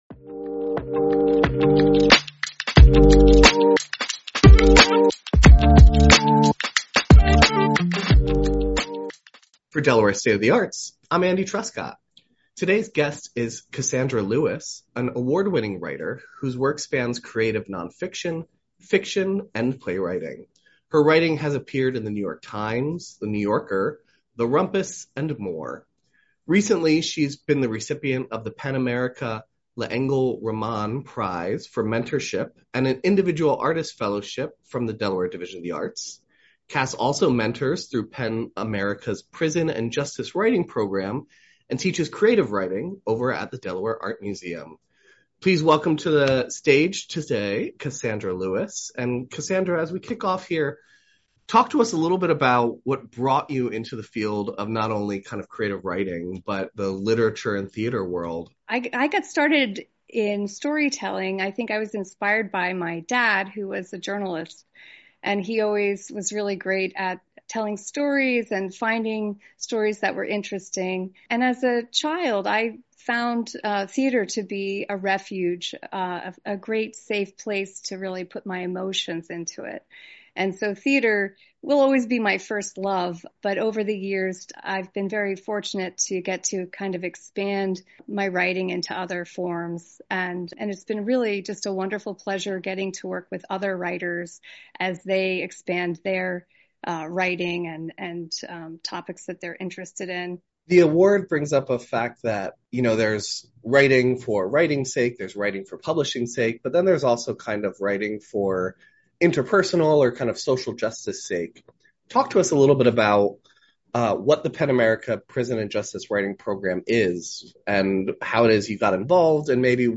The conversation explores how incarcerated writers consistently return to themes of community and connection in their work.